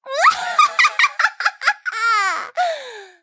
willow_kill_vo_01.ogg